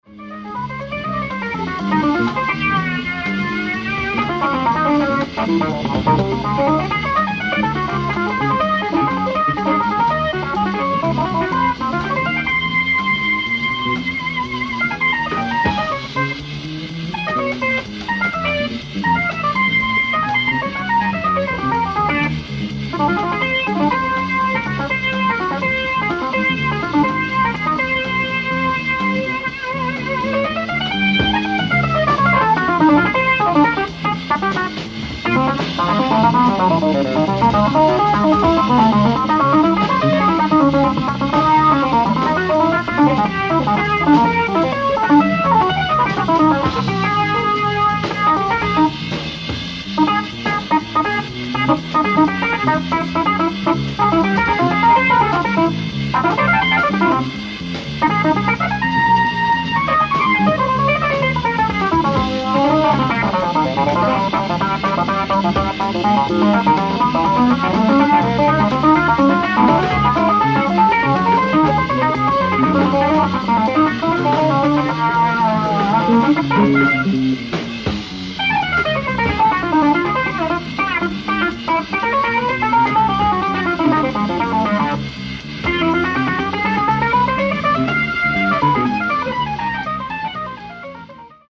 ライブ・アット・ザ・ドック・レストラン、ティブロン、カリフォルニア 10/31/1986
※試聴用に実際より音質を落としています。